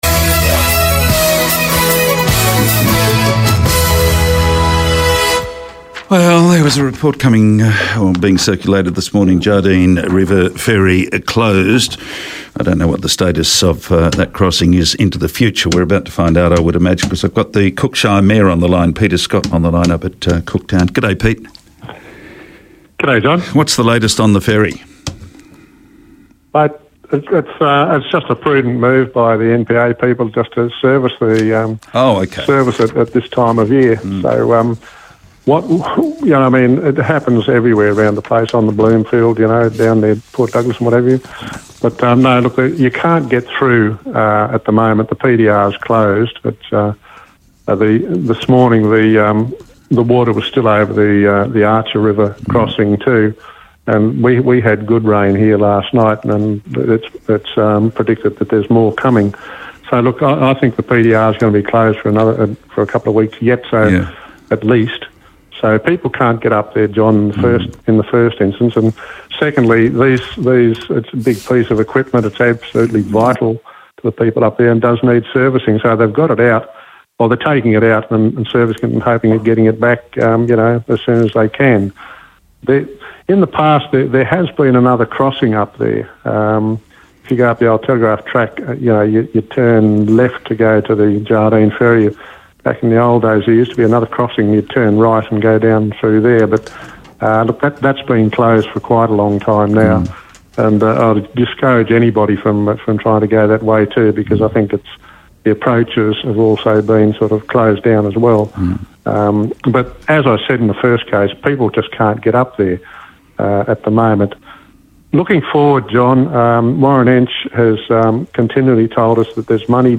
Today I spoke to Peter Scott, the Mayor of Cook Shire, about the Jardine River ferry Closure. Listen below to our discussion.